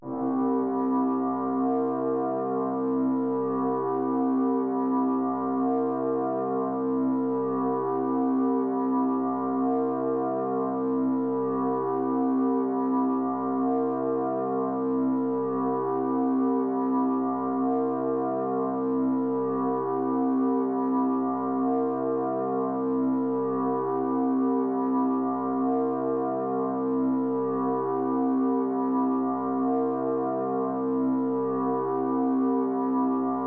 drone3.wav